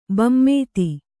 ♪ bammēti